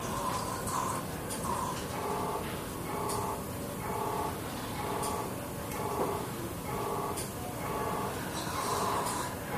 Intensive Care Unit Machinery Glugs,Pump, Pressure